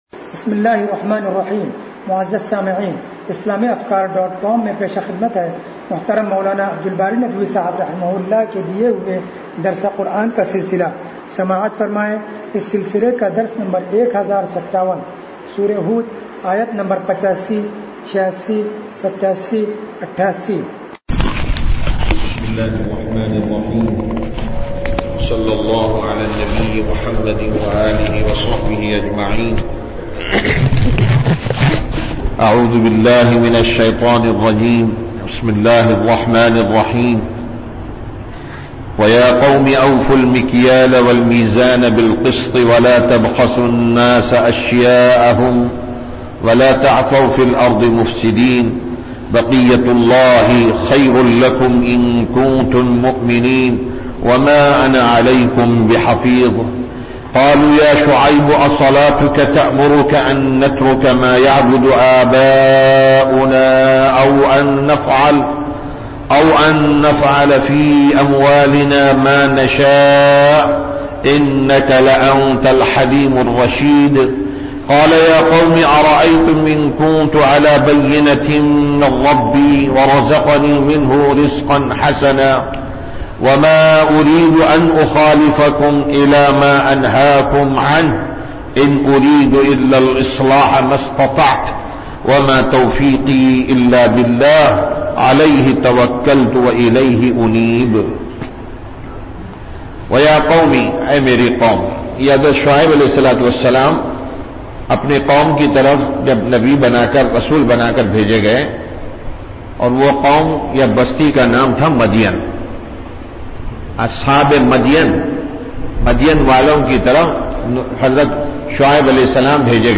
درس قرآن نمبر 1057